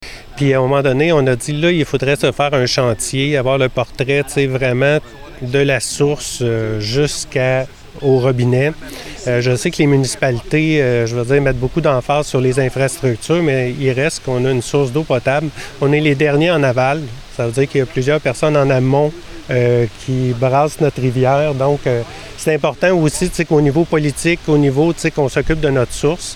Mardi, a Ville a convié les médias pour faire part des projections à venir pour son usine de traitement de l’eau potable.
Comme l’a indiqué le conseiller municipal et président du Chantier sur l’eau potable, Stéphane Biron, il faut se projeter dans le temps pour que la Ville puisse maintenir un service adéquat à sa population.